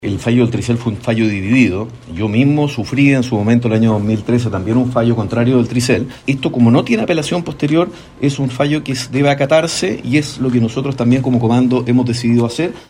El senador de Evópoli, Luciano Cruz Coke, resignado, asumió que se trata de un fallo inapelable, por lo que solo queda acatarlo, al igual como le ocurrió personalmente el año 2013.